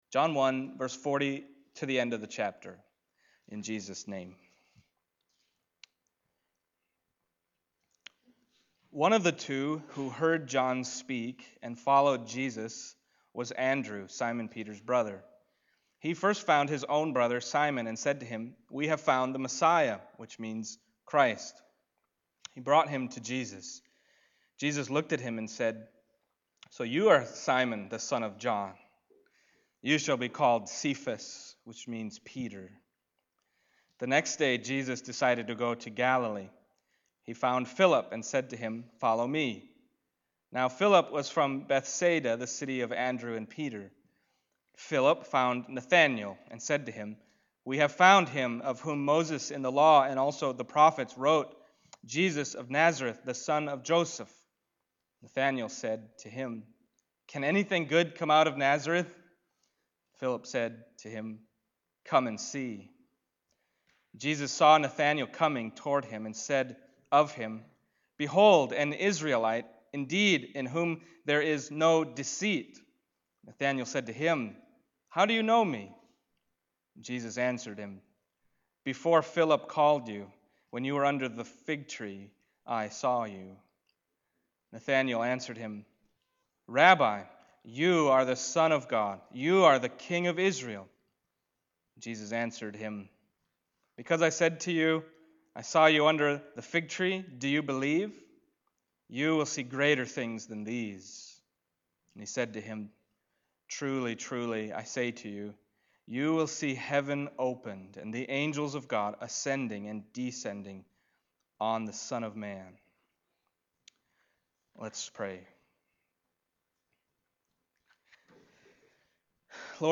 John 1:1-51 Service Type: Sunday Morning John 1:1-51 « We Have Found Him …